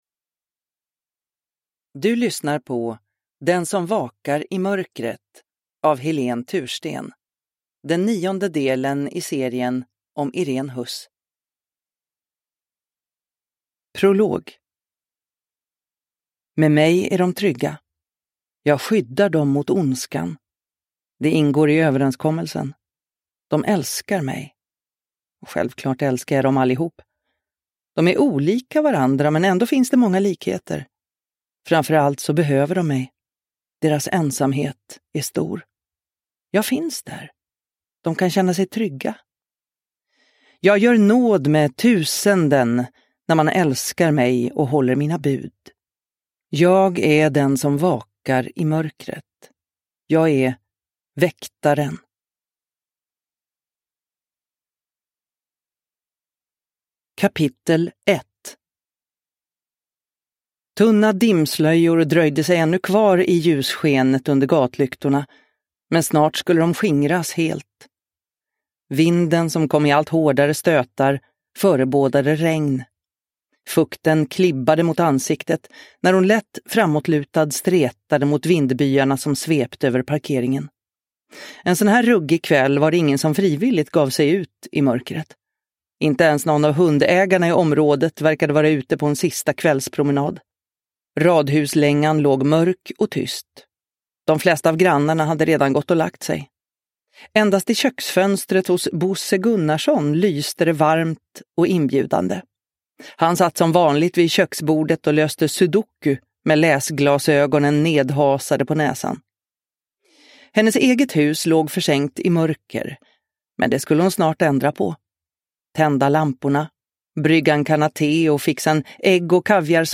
Den som vakar i mörkret – Ljudbok – Laddas ner